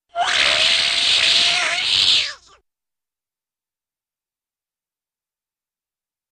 Cat Yowls, Long ( I.e. Step On Cat's Tail )